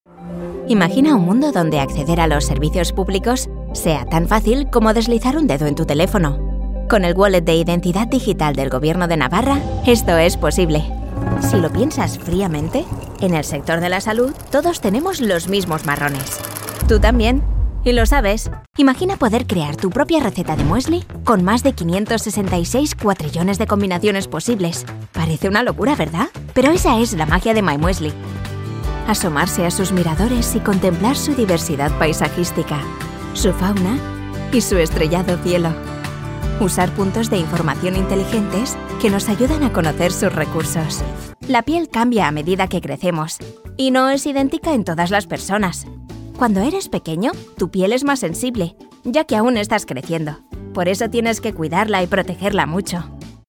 Jong, Natuurlijk, Veelzijdig, Warm, Zacht
Explainer
A versatile voice that can reach audiences with a sweet, natural and friendly vibe, she can also bring an emotional depth, or perhaps a serious and mature tone for business presentations.